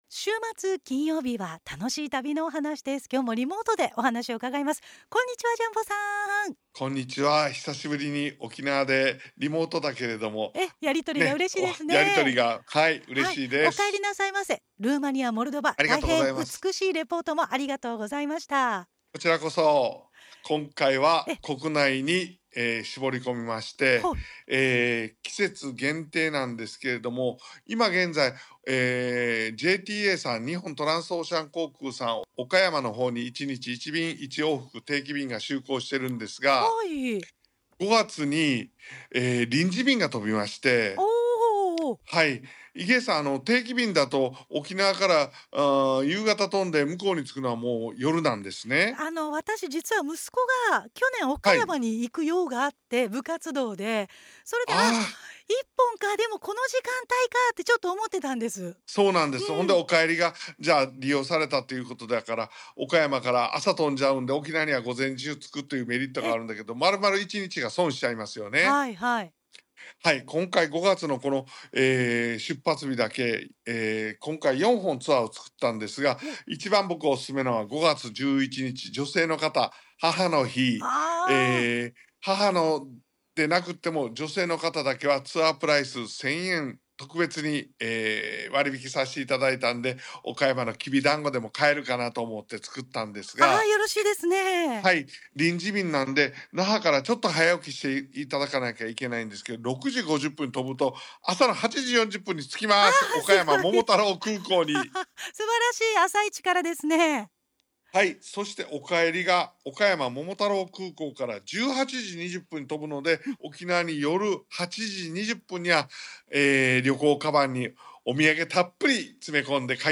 ★『絶景かな!絶景かな!素晴らしき「しまなみ海道」から始まる四国・小豆島・倉敷へ3日間』2025年4月18日(金)ラジオ放送